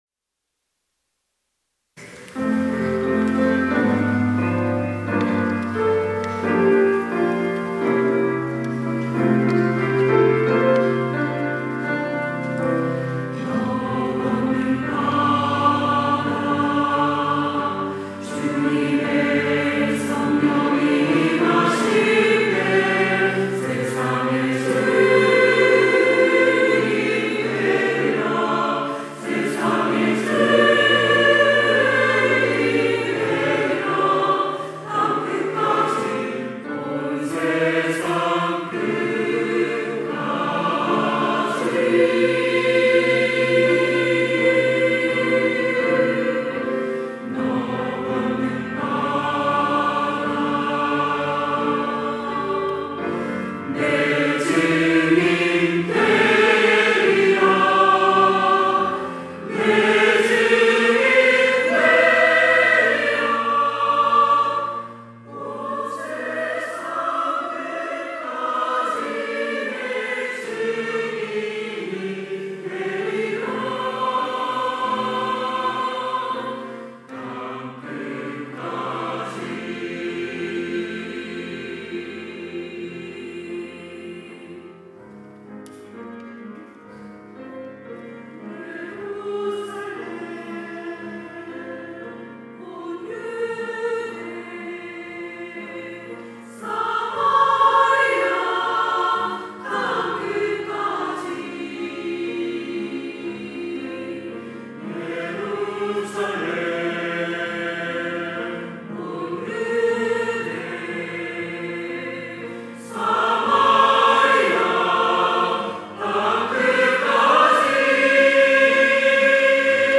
호산나(주일3부) - 증인이 되리라
찬양대